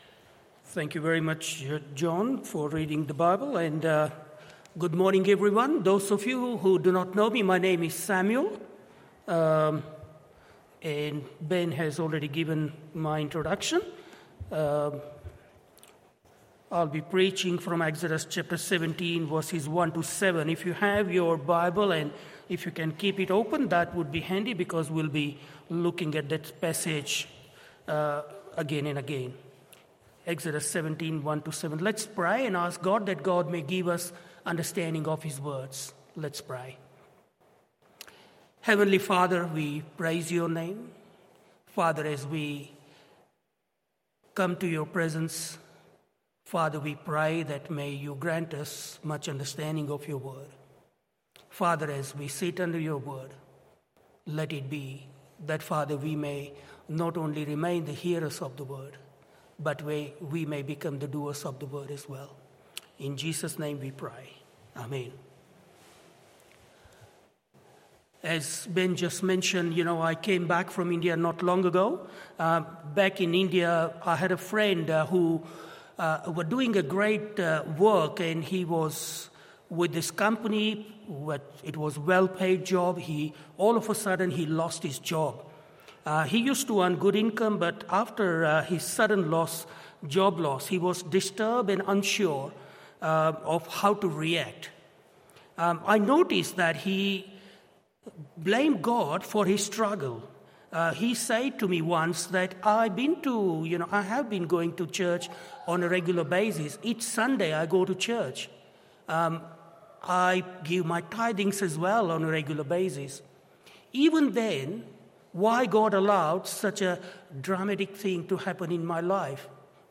Morning Service Exodus 17:1-17…